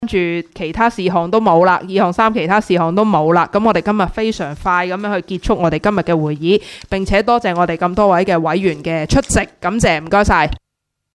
工作小组会议的录音记录